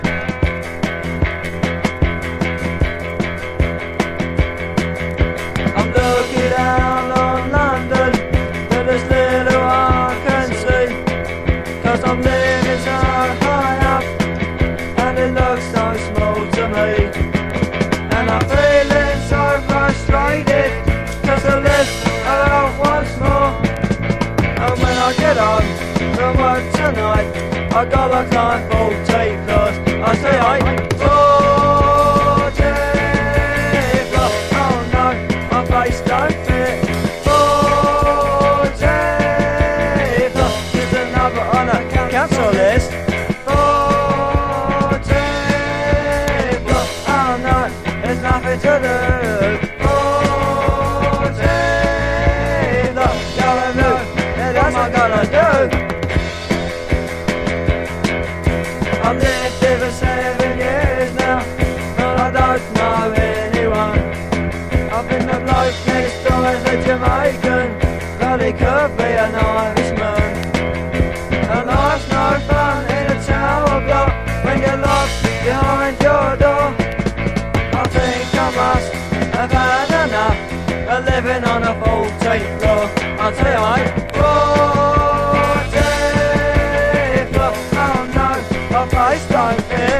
形式 : 7inch / 型番 : / 原産国 : UK
オリジナル・パンクの影響が伺えるヘロヘロなパンク・チューン!!